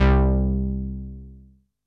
MOOG INT 1.wav